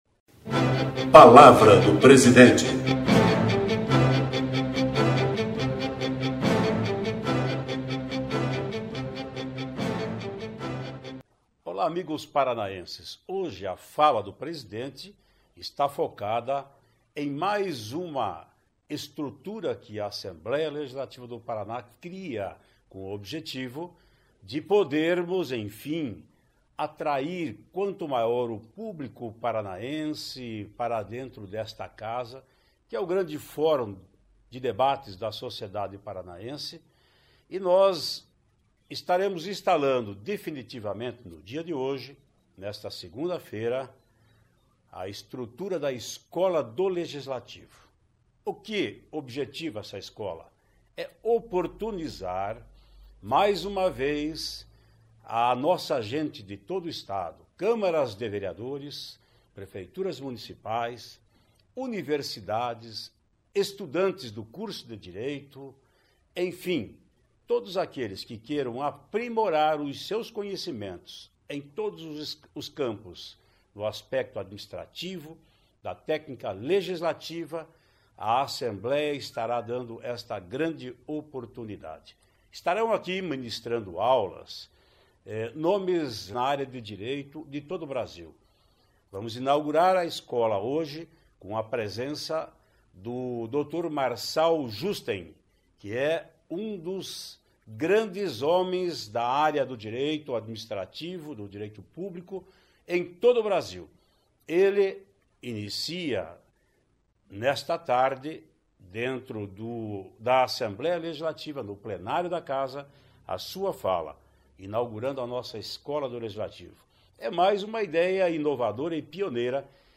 Ouça o que diz o presidente da Assembleia, Ademar Traiano (PSDB) no "Fala Presidente" de hoje.